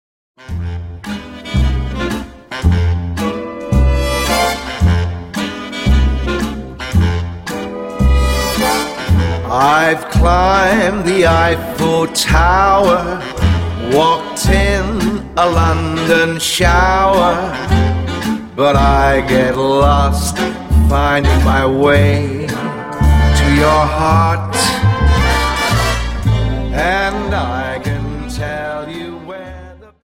Dance: Slowfox 29 Song